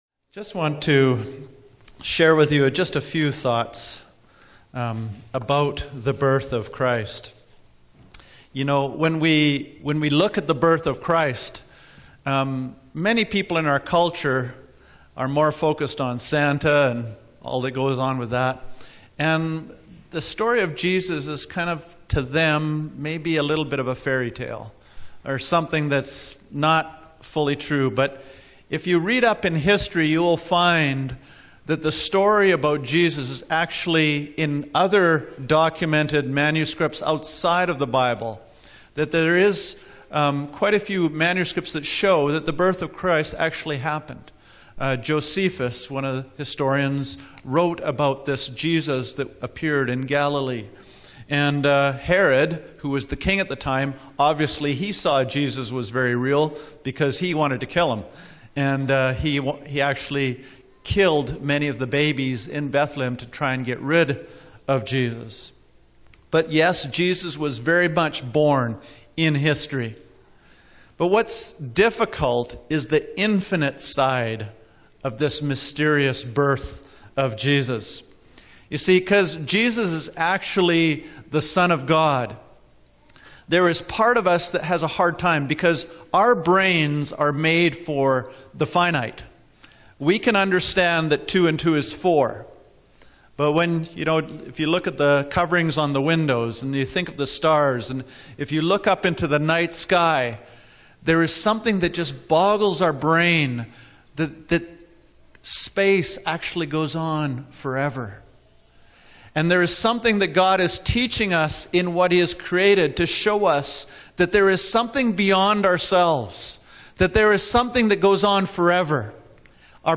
A Christmas Message